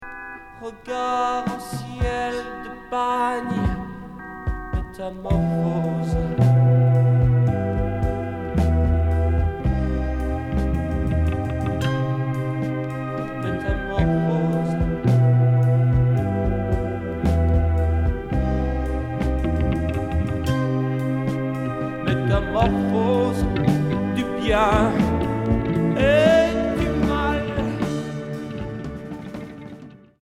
Progressif Troisième 45t retour à l'accueil